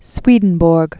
(swēdn-bôrg, svādn-bôrē), Emanuel 1688-1772.